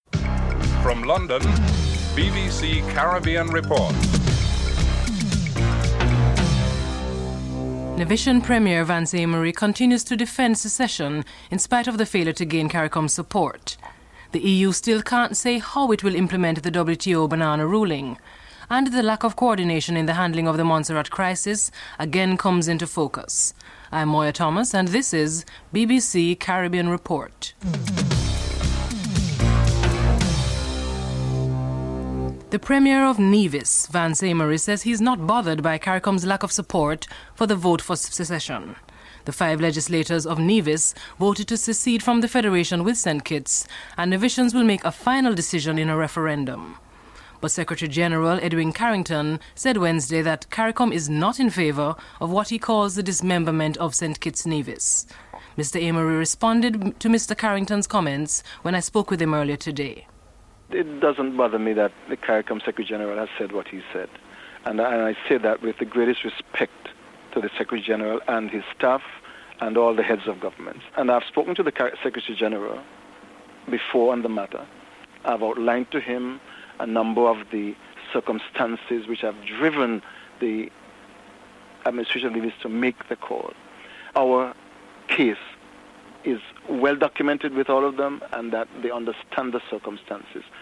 1. Headlines (00:00-00:30)
Premier Amory Vance is interviewed (00:31-04:21)
Member of Parliament Bernie Grant is interviewed (10:00-11:19)